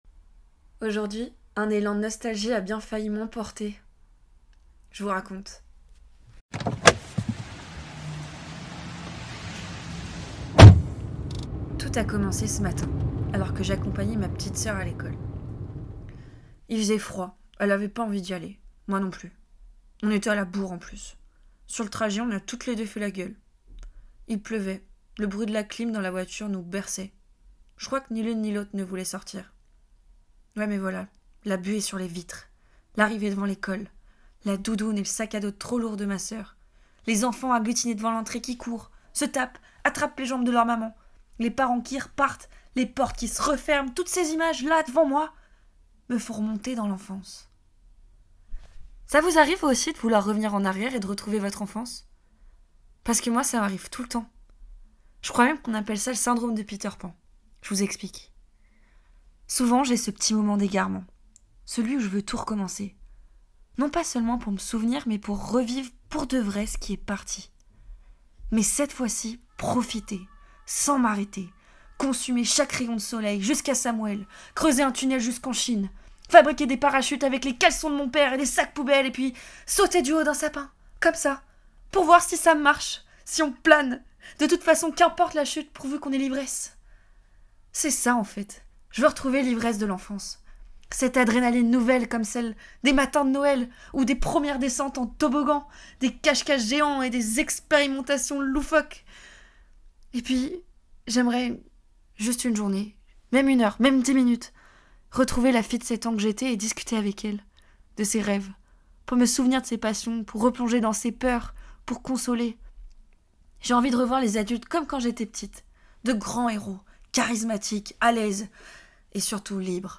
Voix off
17 - 30 ans - Mezzo-soprano